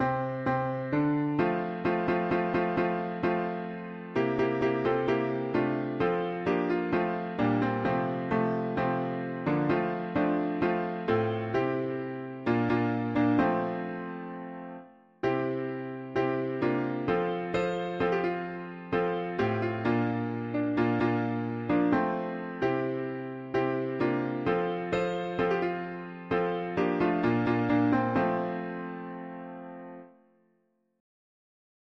Music: African American Spiritual Key: F major
Tags english theist 4part chords